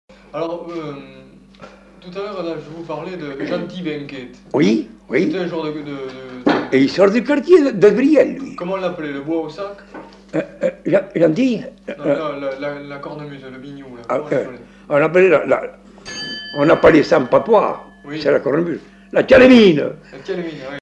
Aire culturelle : Bazadais
Genre : témoignage thématique